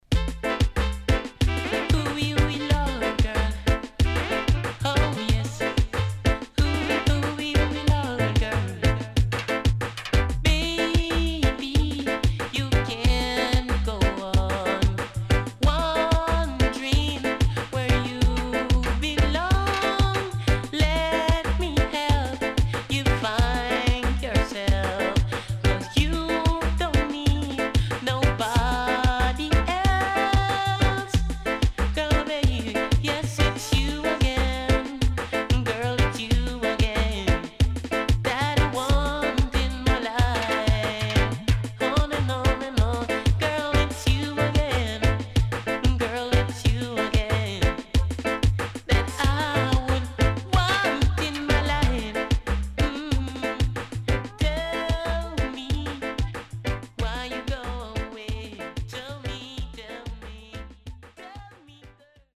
HOME > LP [DANCEHALL]
SIDE A:少しチリノイズ入りますが良好です。